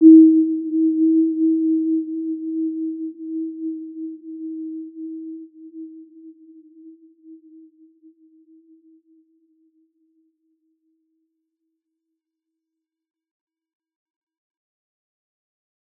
Warm-Bounce-E4-p.wav